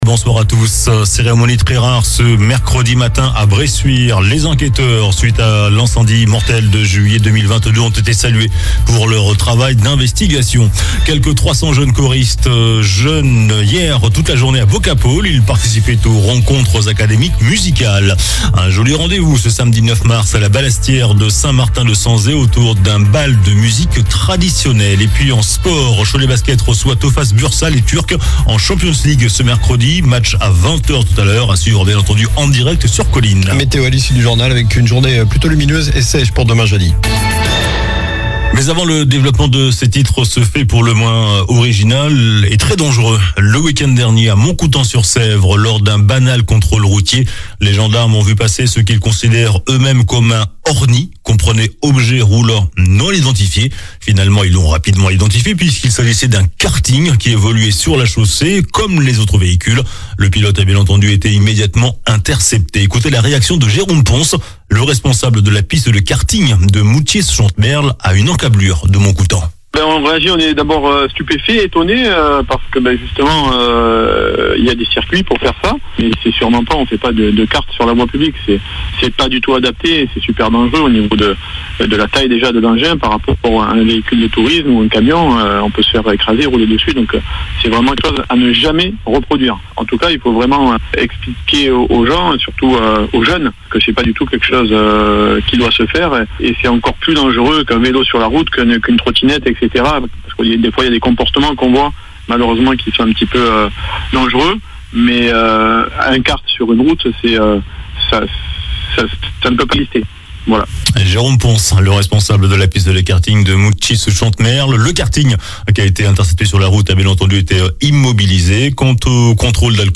JOURNAL DU MERCREDI 06 MARS ( SOIR )